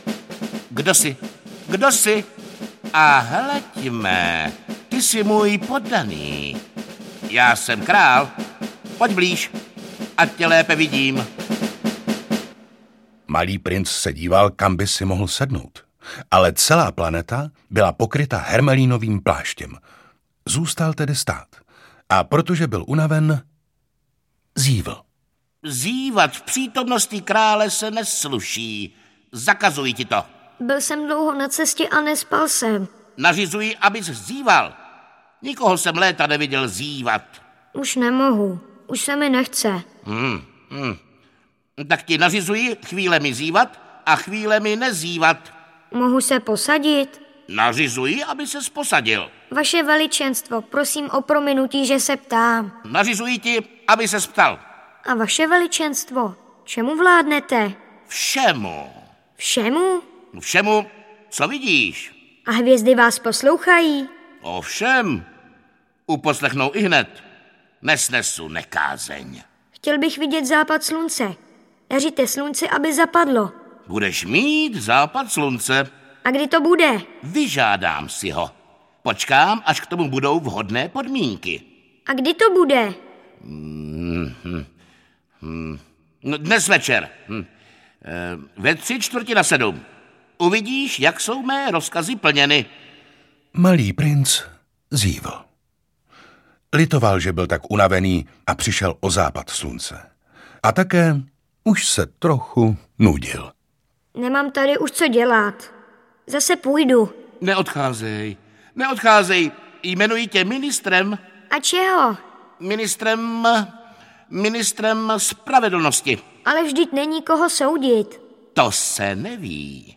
Malý princ - dramatizace audiokniha
Nové zpracování Malého prince formou dramatizace přístupné malým i velkým posluchačům!
maly-princ-dramatizace-audiokniha